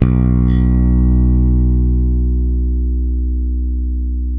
-JP ROCK B 2.wav